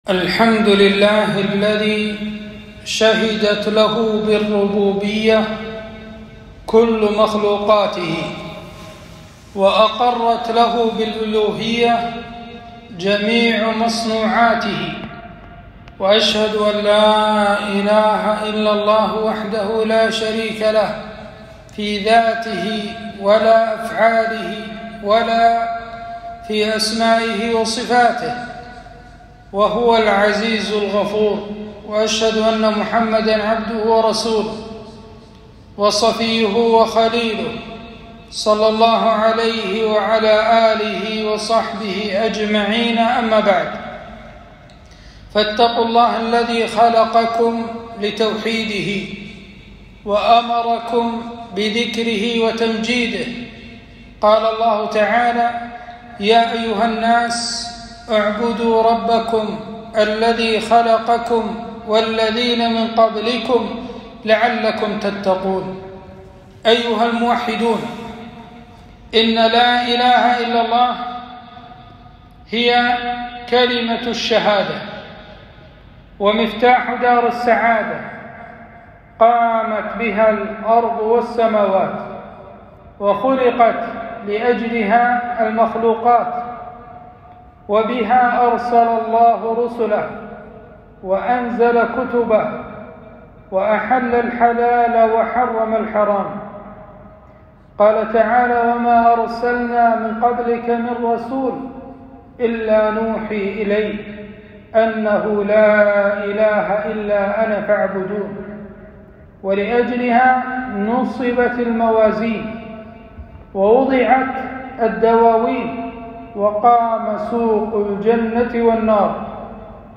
خطبة - التوحيد يا عباد الله